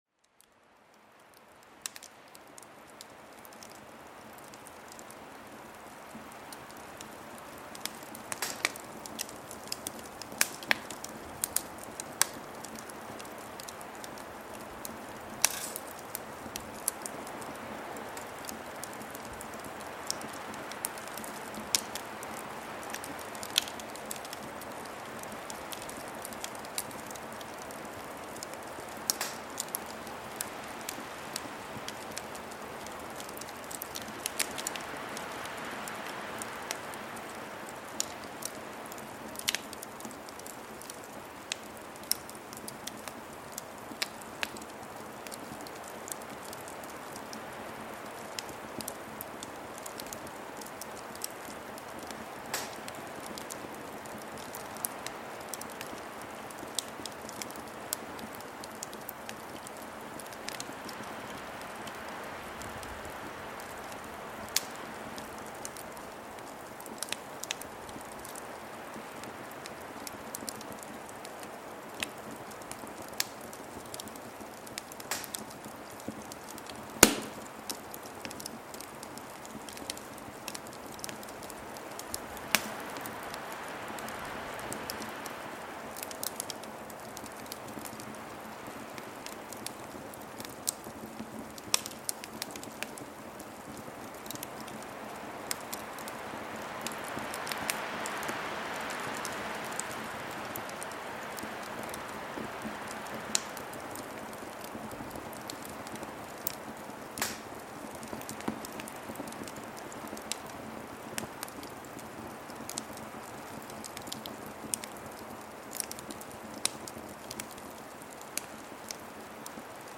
SOFORTIGE GEBORGENHEIT: Fenster-Zauber mit Schneeflocken + Feuer